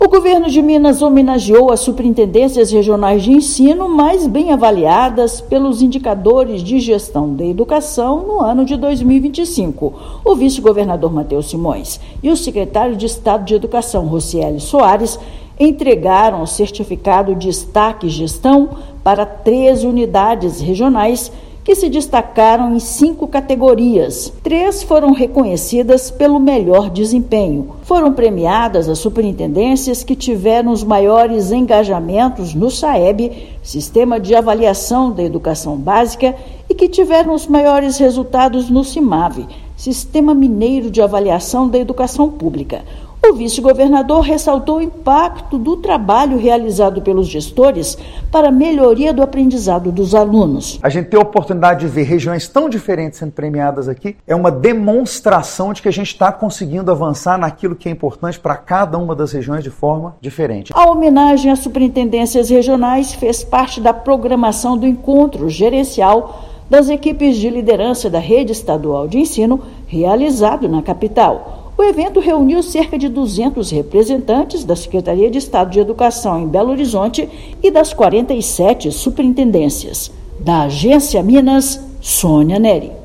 Premiação foi entregue durante o Encontro Gerencial, que reuniu cerca de 200 gestores para discutir resultados educacionais e prioridades pedagógicas. Ouça matéria de rádio.